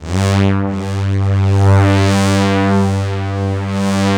110 PAD G2-R.wav